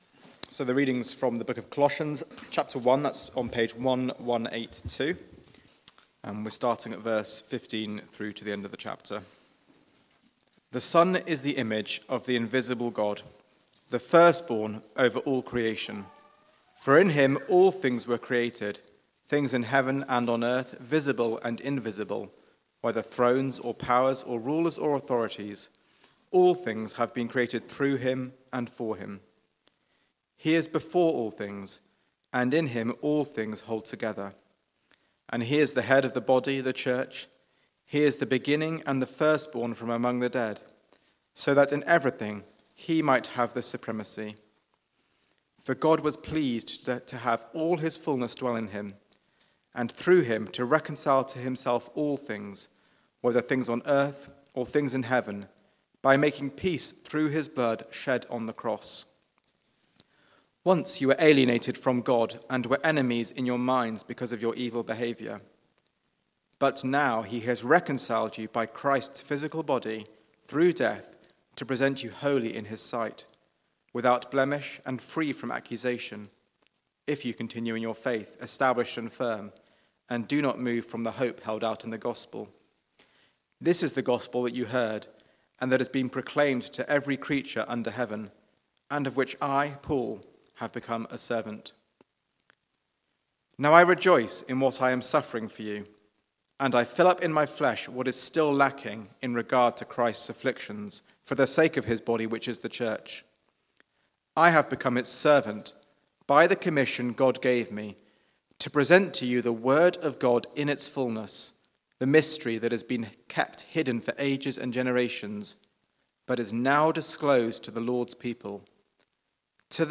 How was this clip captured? Media for Church at the Green Sunday 4pm